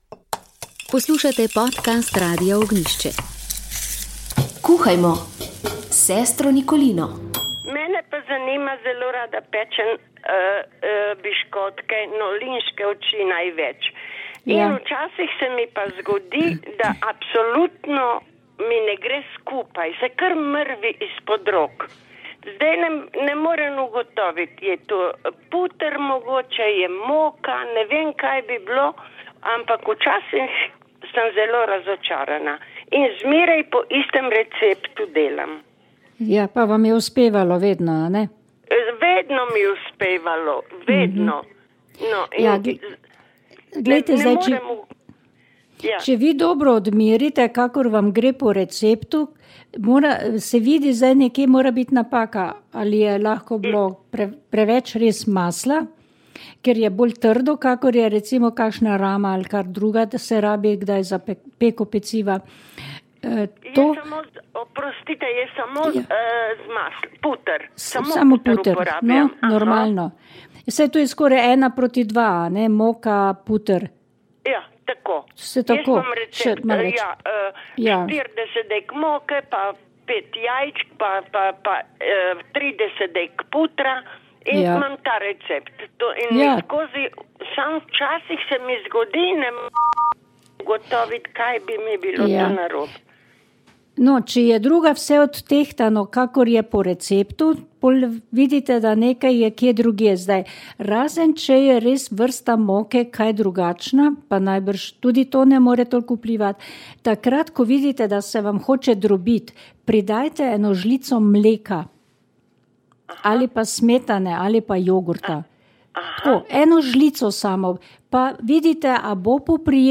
violoncelo
koncert dobrodelnost